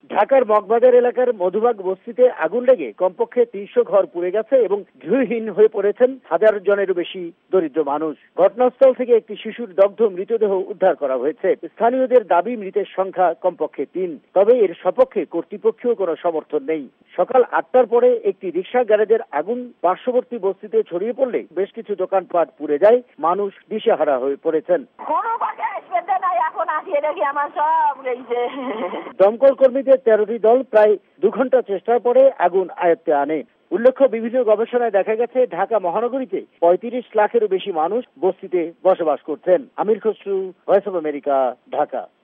বাংলাদেশ সংবাদদাতাদের রিপোর্ট